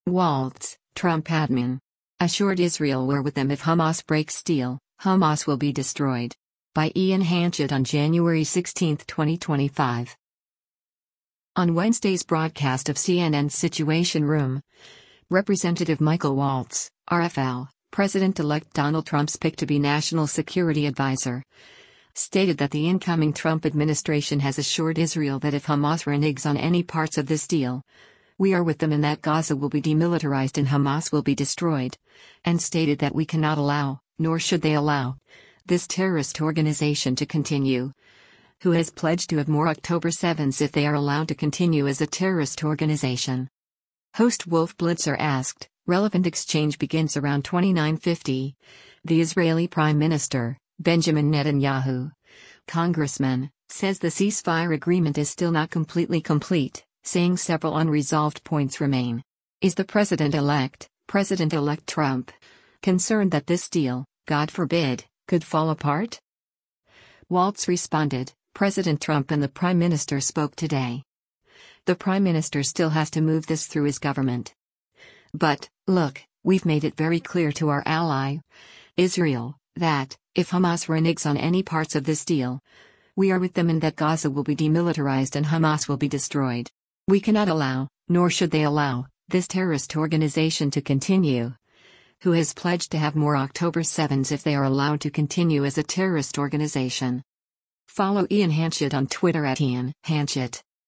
On Wednesday’s broadcast of CNN’s “Situation Room,” Rep. Michael Waltz (R-FL), President-Elect Donald Trump’s pick to be national security adviser, stated that the incoming Trump administration has assured Israel that “if Hamas reneges on any parts of this deal, we are with them and that Gaza will be demilitarized and Hamas will be destroyed.”
Host Wolf Blitzer asked, [relevant exchange begins around 29:50] “The Israeli Prime Minister, Benjamin Netanyahu, Congressman, says the ceasefire agreement is still not completely complete, saying several unresolved points remain.